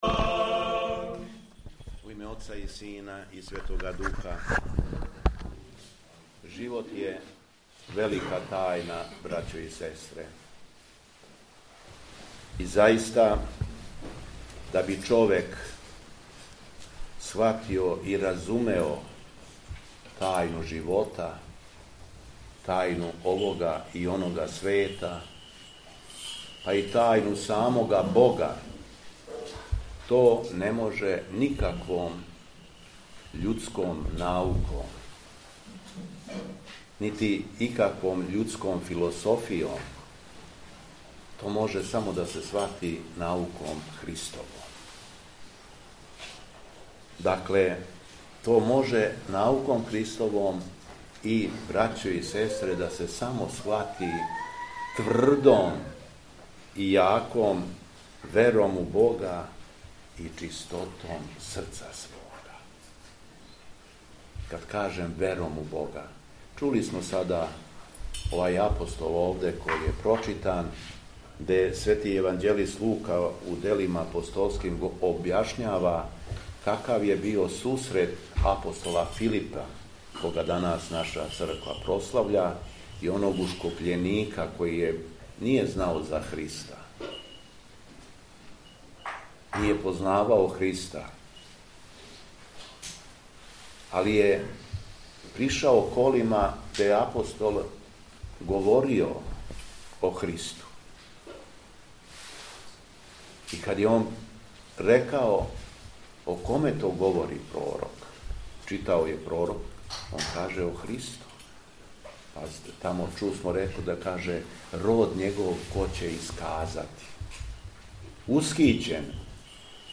Беседа Његовог Високопреосвештенства Митрополита шумадијског г. Јована
За благољепије сабрања били су задужени појци манастира Светог великомученика Георгија.
Надахнутом беседом Митрополит Јован је поучавао окупљене вернике: